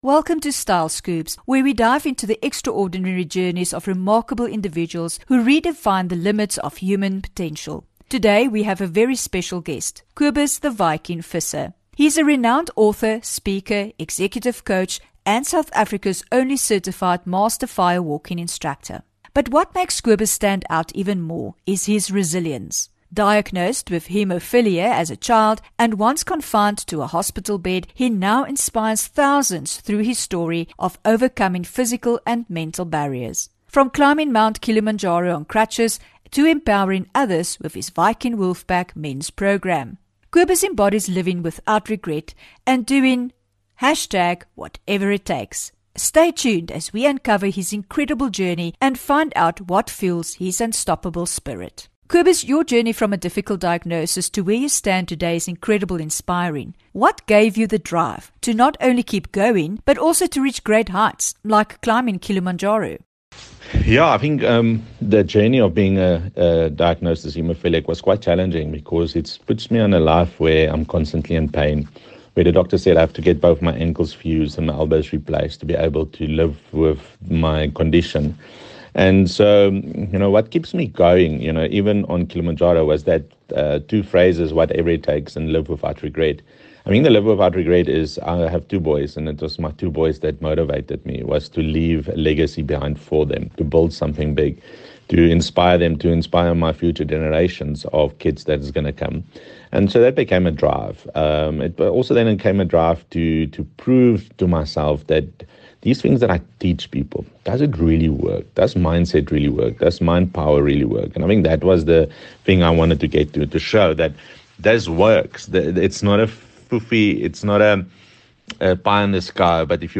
19 Nov INTERVIEW